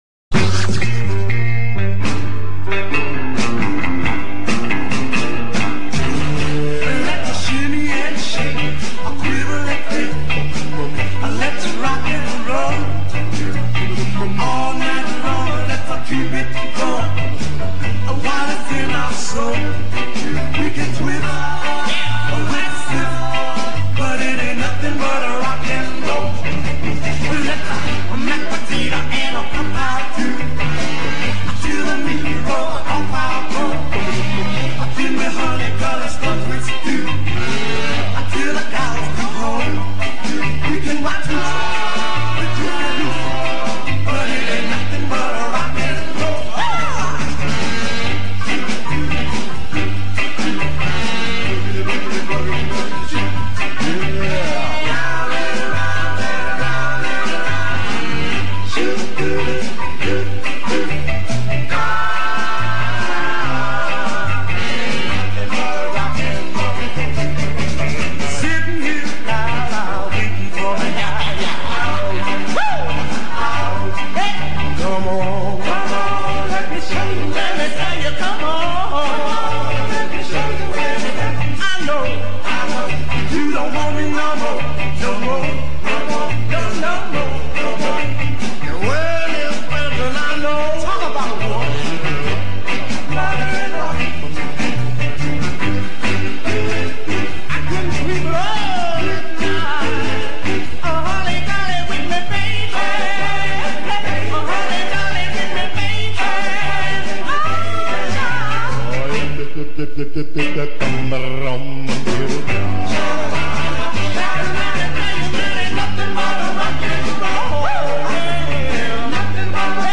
Tag: Doo Wop
Puerto Rican street corner vocal group